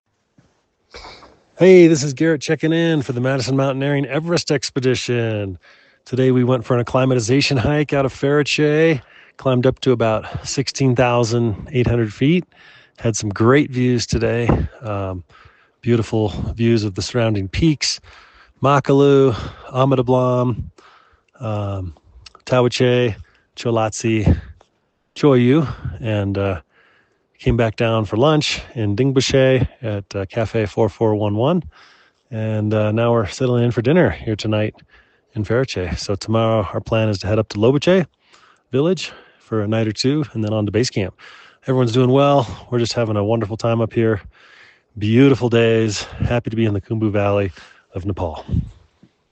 checked in with this dispatch from Pheriche: